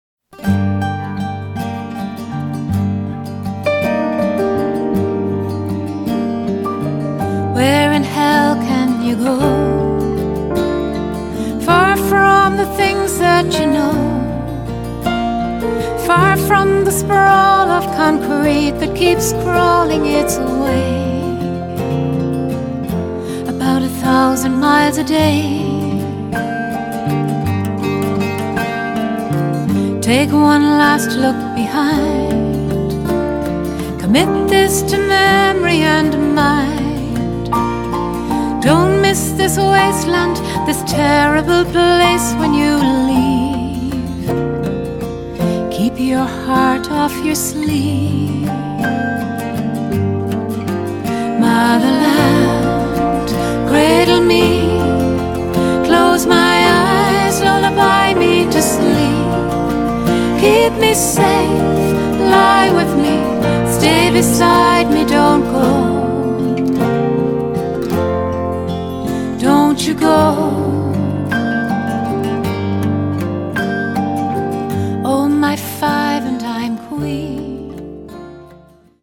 Backing Vocals
Bodhrán
Piano
the album is a collection of 13 eclectic songs and tunes.